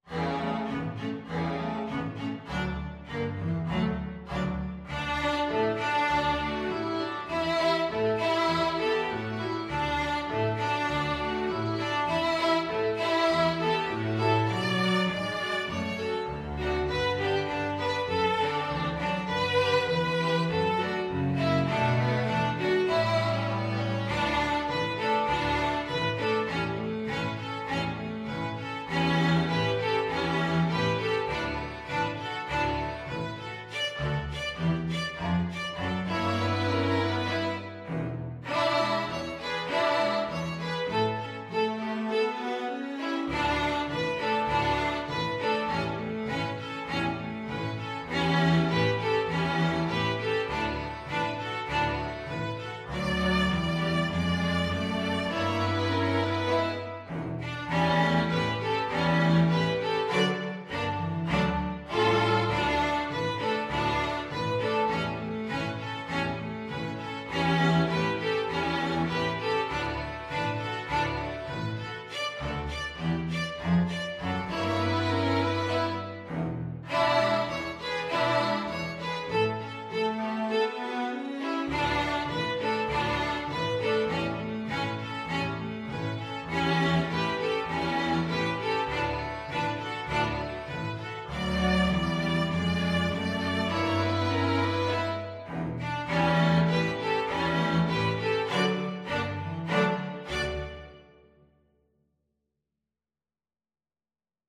Violin 1Violin 2ViolaCelloDouble Bass
2/2 (View more 2/2 Music)
Moderato =c.100
Pop (View more Pop String Ensemble Music)